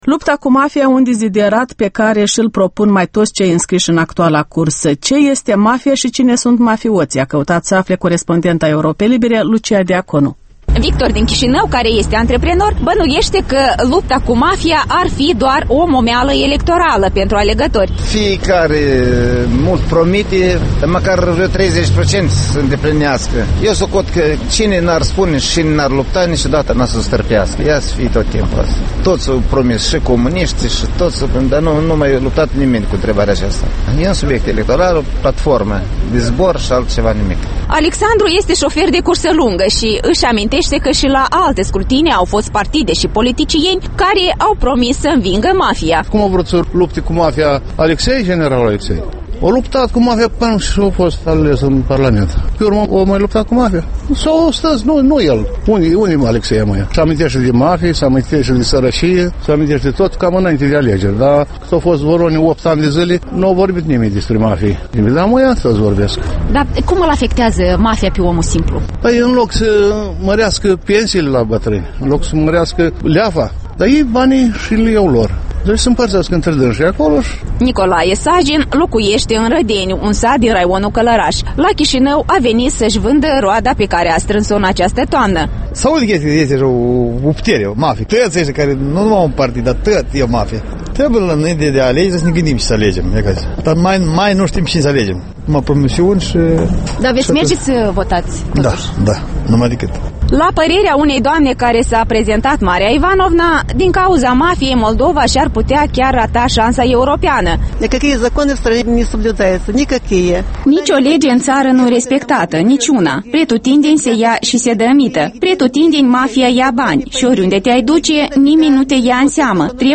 Reportaj electoral: Lupta cu Mafia - opinii și convingeri